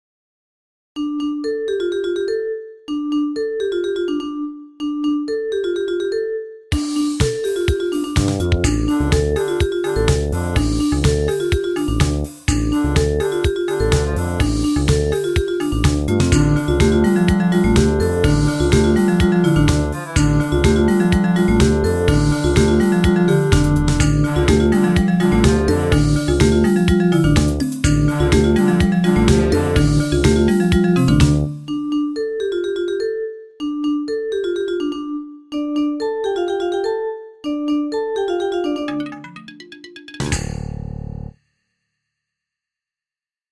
DISCO MUSIC